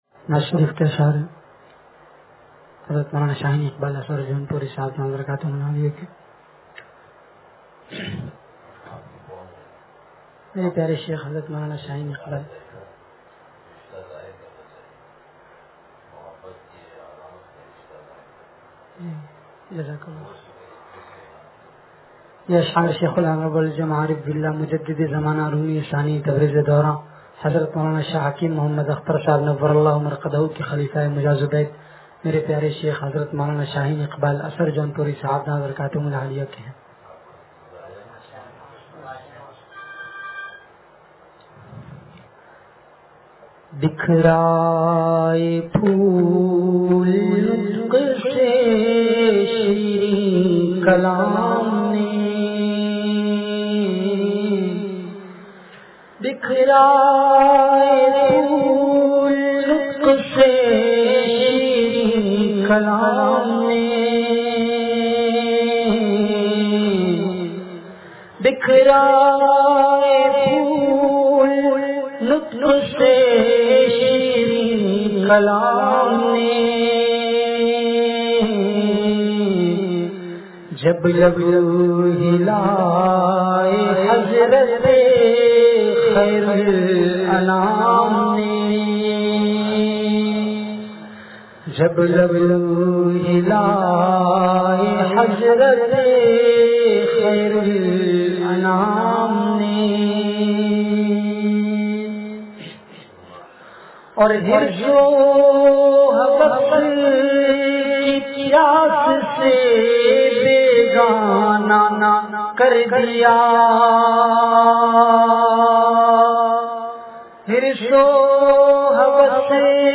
مجلس بروز جمعرات